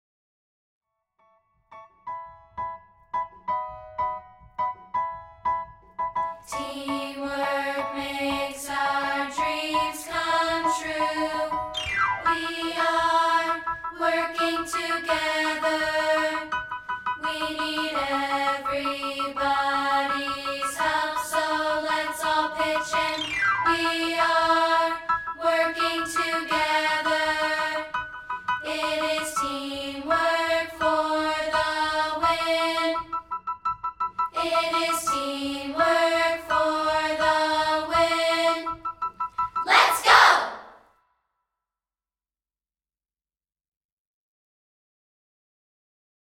We're offering a rehearsal track of part 2, isolated